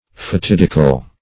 Search Result for " fatidical" : The Collaborative International Dictionary of English v.0.48: Fatidical \Fa*tid"i*cal\, a. [L. fatidicus; fatum fate + dicere to say, tell.]